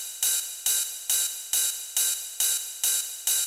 OP HH     -R.wav